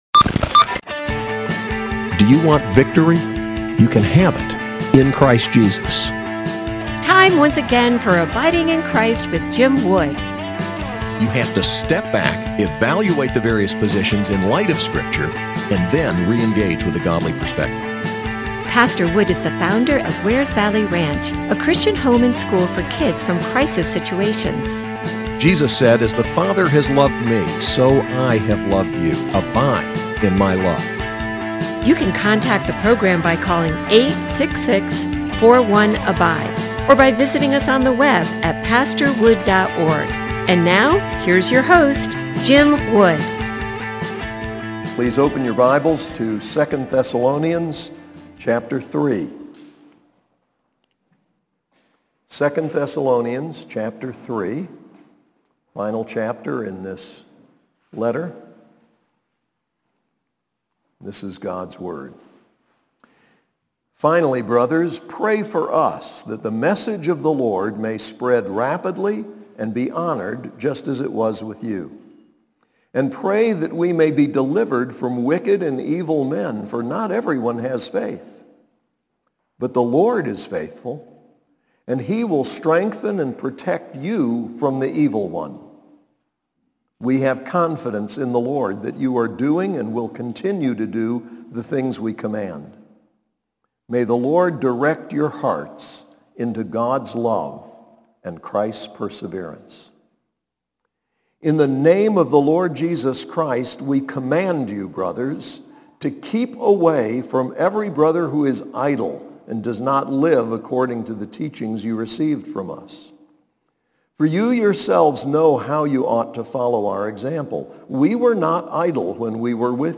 SAS Chapel